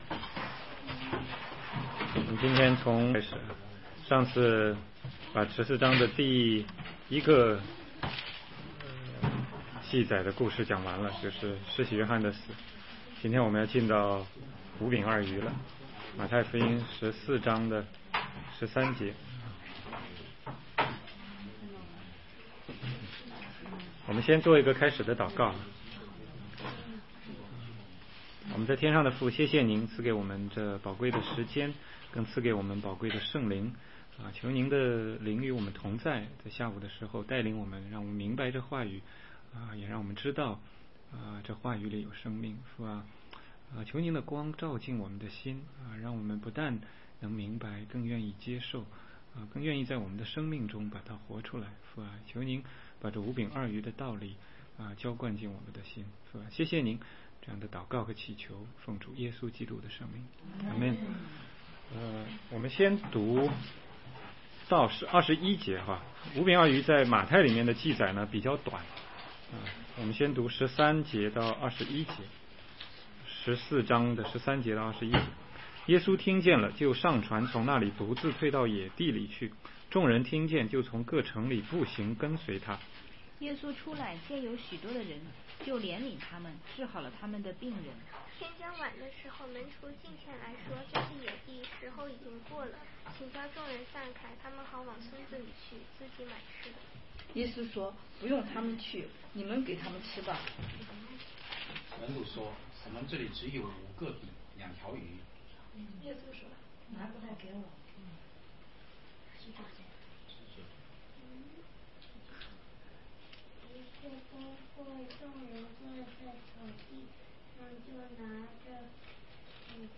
16街讲道录音 - 马太福音14章13-33节